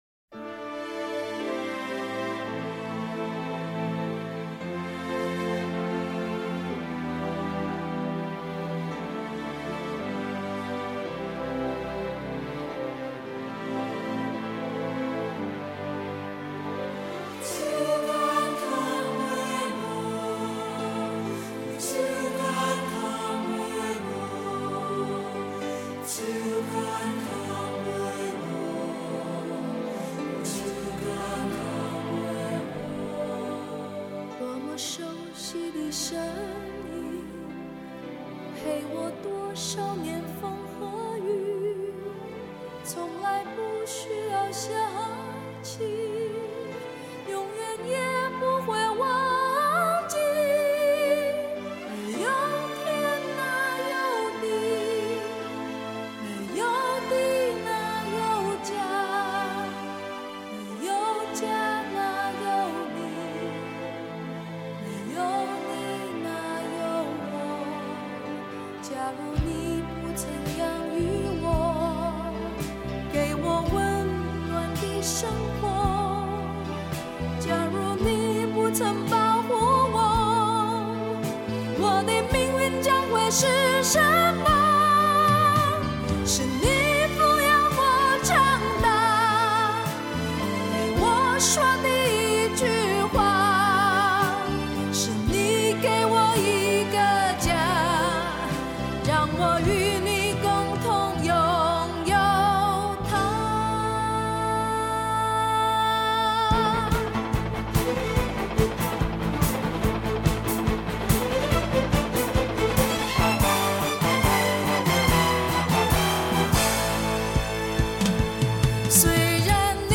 一个特立独行的女声，每一句的唱法都是不同于当时主流的民歌女歌手，也不知道什么因缘际会在此一吼惊世。